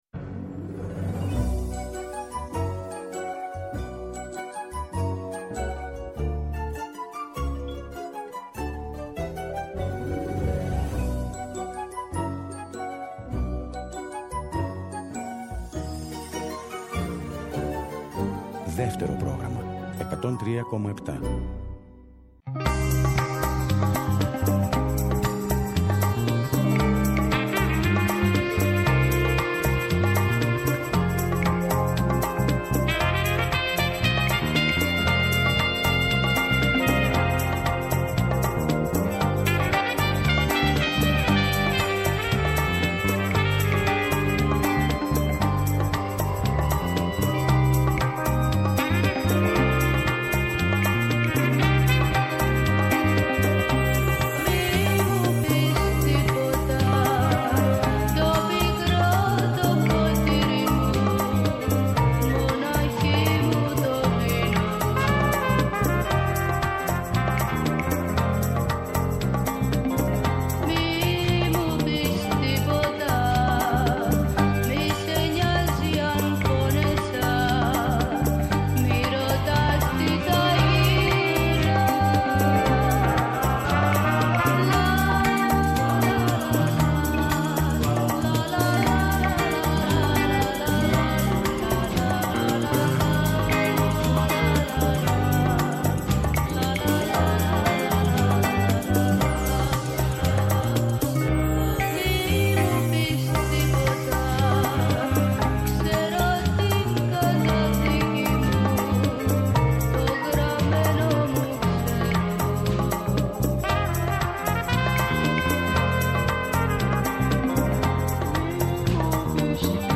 «Άλλη μια μέρα» στον αέρα του Δεύτερου, εκπομπή καλής διάθεσης και μουσικής, για την ώρα που η μέρα φεύγει και η ένταση της μέρας αναζητά την ξεκούραση και τη χαρά της παρέας.